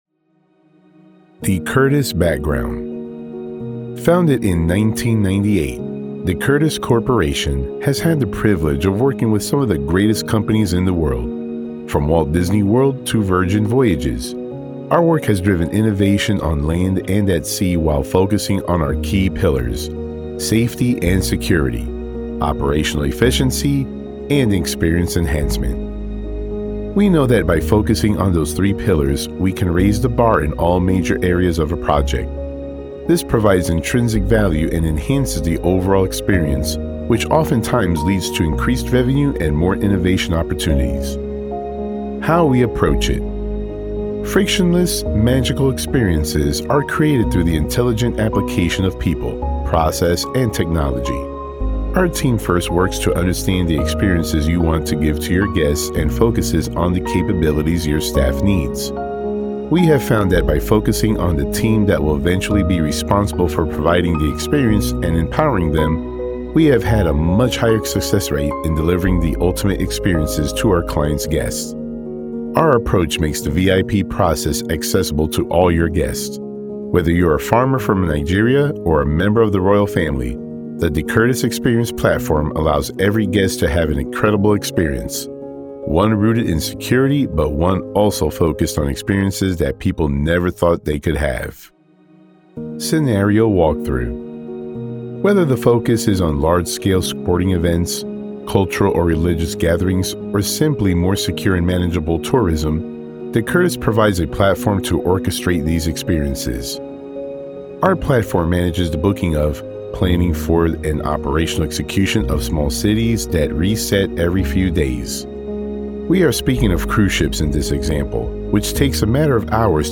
4 Minute Presentation